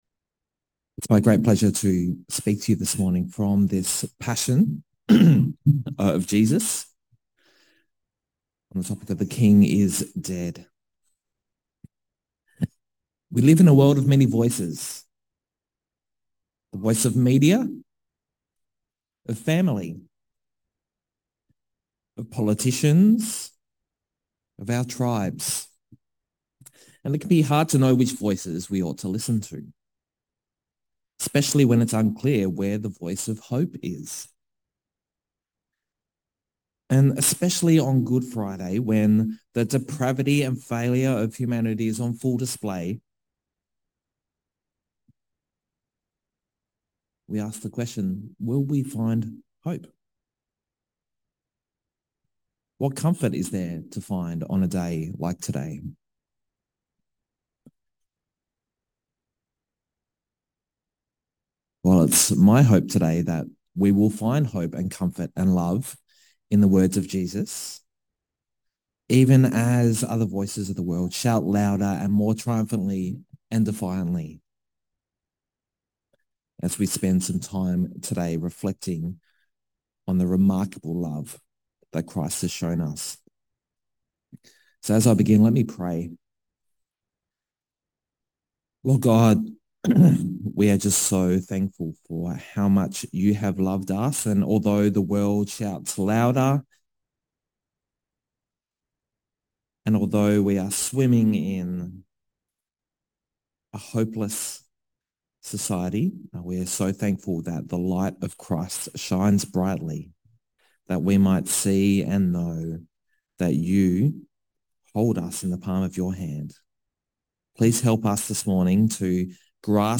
2023 The King is Dead Preacher